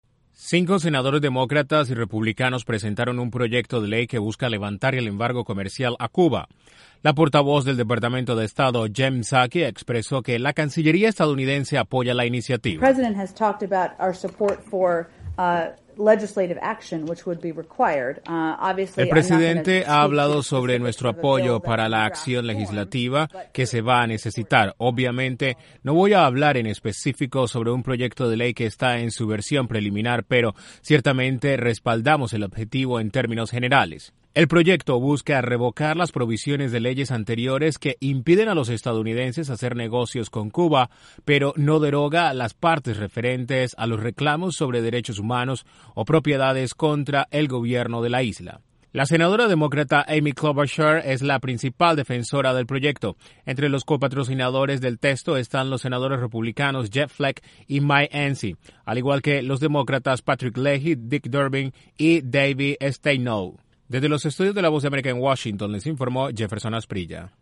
Un grupo bipartidista de senadores presentaron un proyecto de ley que levantaría el embargo estadounidense a Cuba. Desde la Voz de América en Washington informa